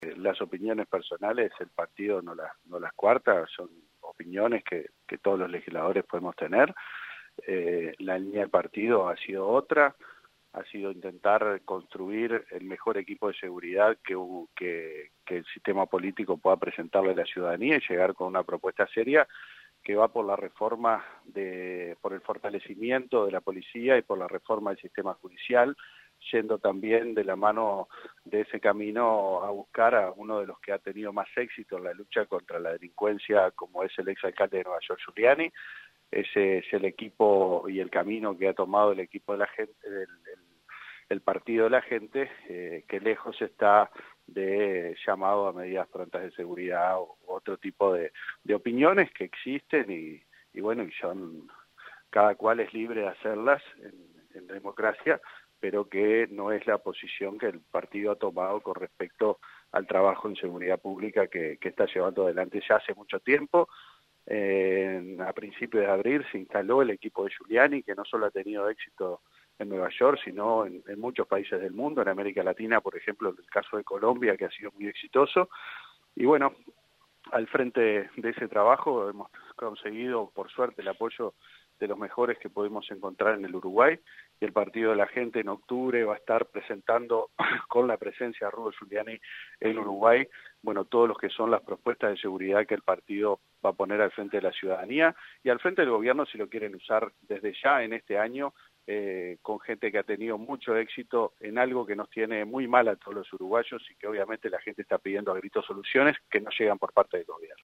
El diputado Adrián Peña, se refirió a lo hablado por Facello y expresó a Informativo Universal que son a titulo personal.